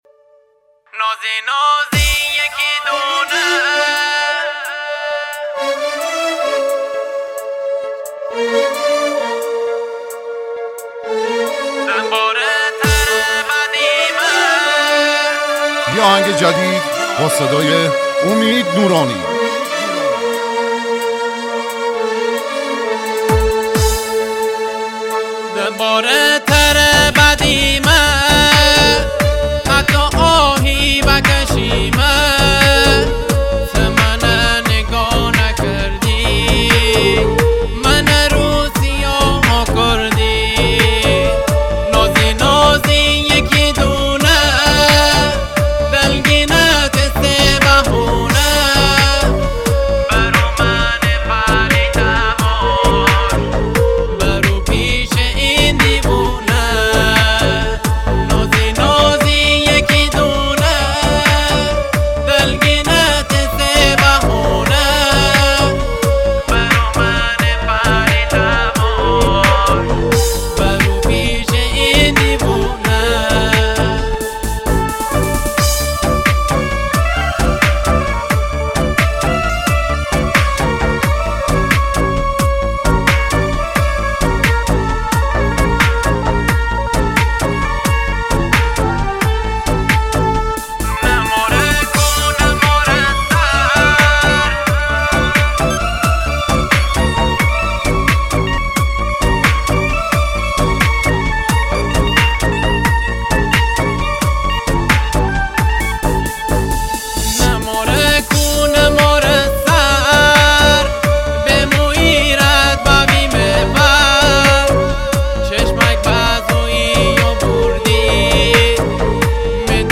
آهنگ فوق العاده شمالی
دانلود آهنگ مازندرانی جدید
موضوع : آهنگ شاد , خوانندگان مازنی ,